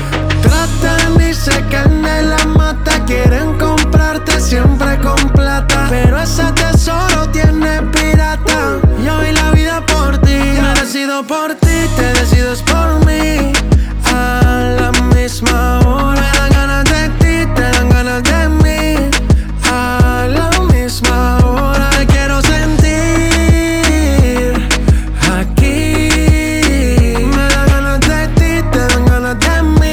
• Urbano latino